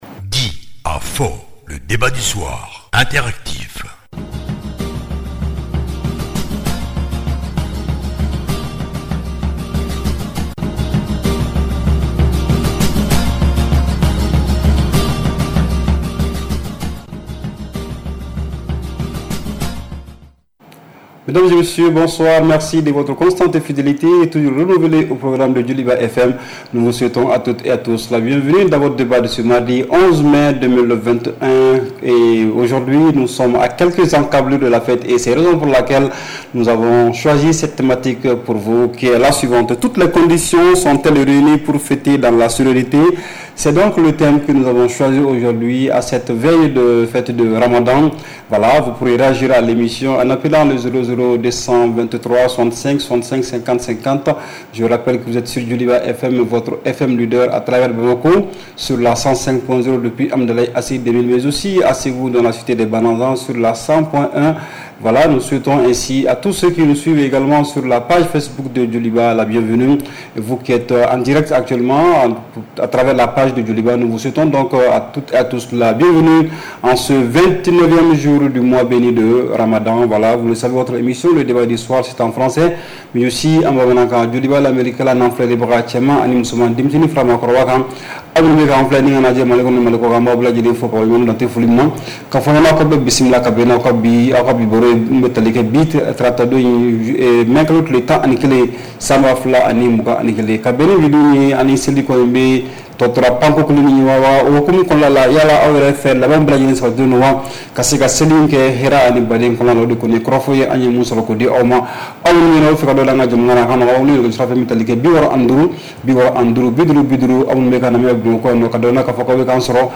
REPLAY 11/05 – « DIS ! » Le Débat Interactif du Soir